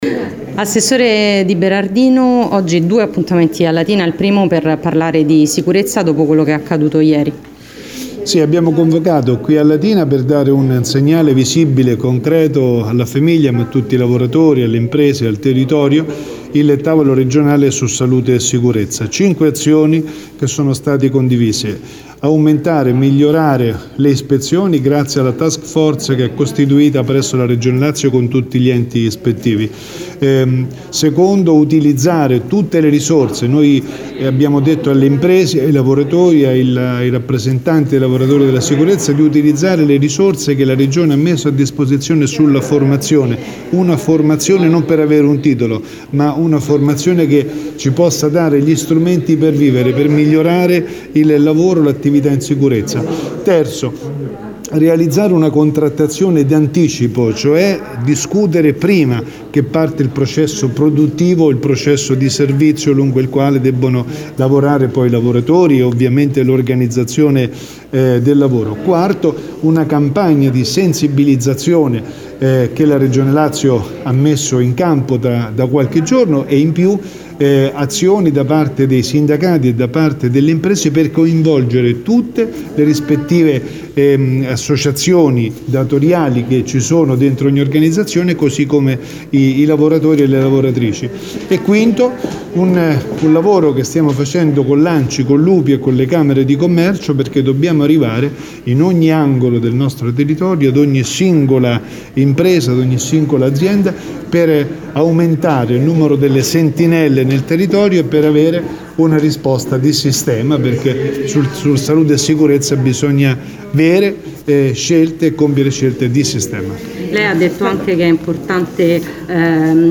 ha spiegato l’assessore al microfono